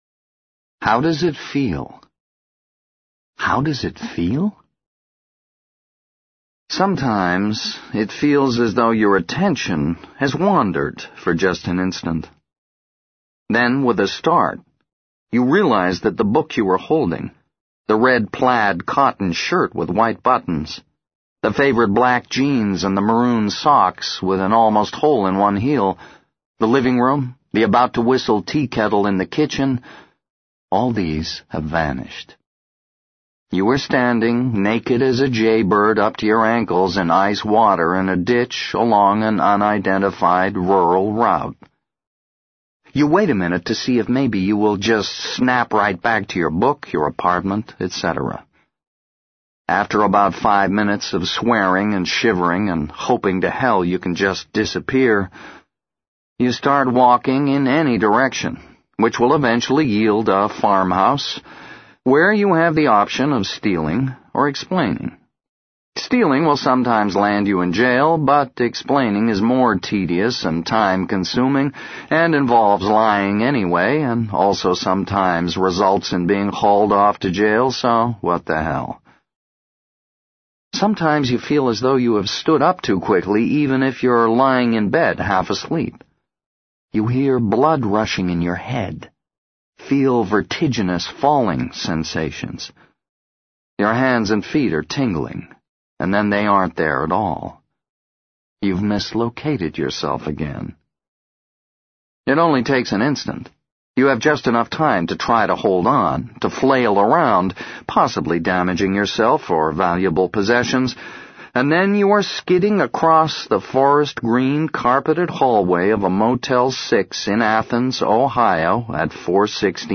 在线英语听力室【时间旅行者的妻子】02的听力文件下载,时间旅行者的妻子—双语有声读物—英语听力—听力教程—在线英语听力室